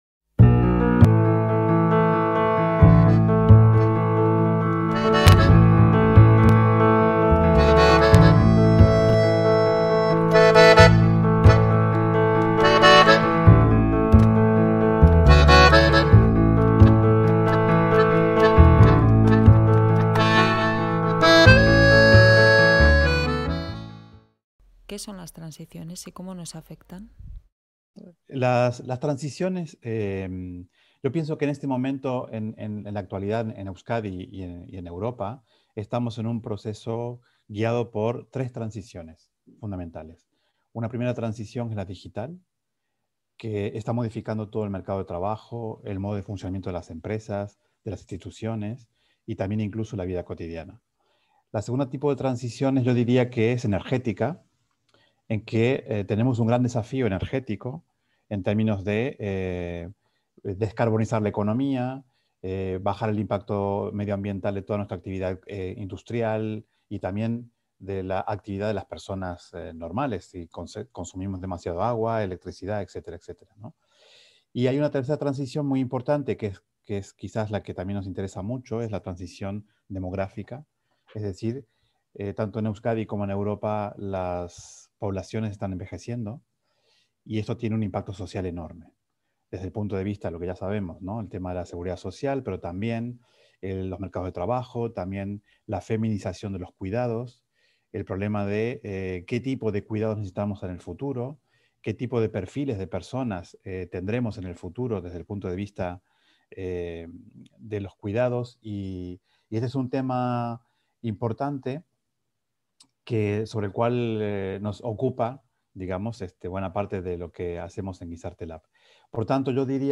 En su conversación